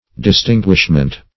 Search Result for " distinguishment" : The Collaborative International Dictionary of English v.0.48: Distinguishment \Dis*tin"guish*ment\, n. Observation of difference; distinction.